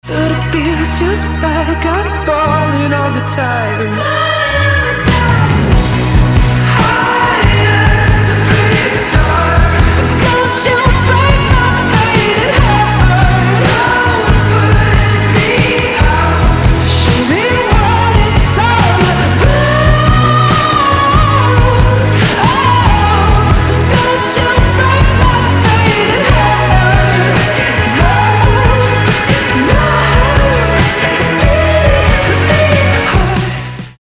• Качество: 320, Stereo
мужской вокал
alternative
indie rock